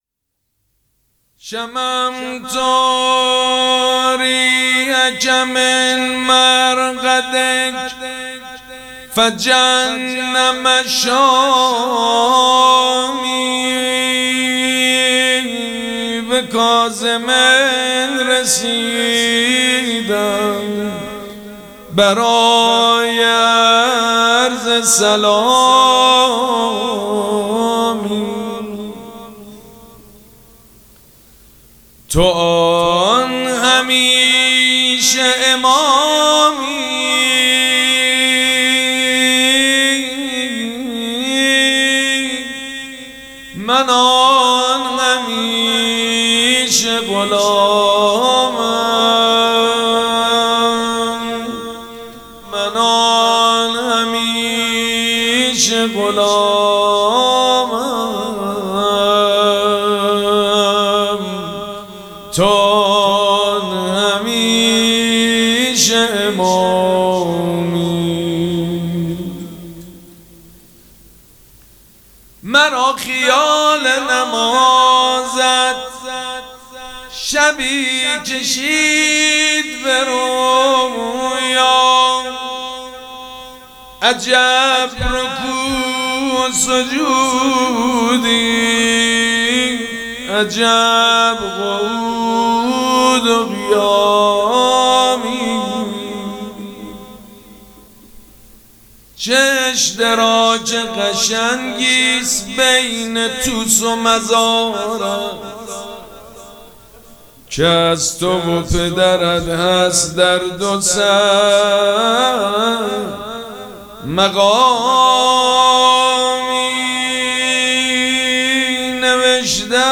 مراسم عزاداری شهادت امام جواد علیه‌السّلام دوشنبه ۵ خرداد ماه ۱۴۰۴ | ۲۸ ذی‌القعده ۱۴۴۶ حسینیه ریحانه الحسین سلام الله علیها
سبک اثــر روضه مداح حاج سید مجید بنی فاطمه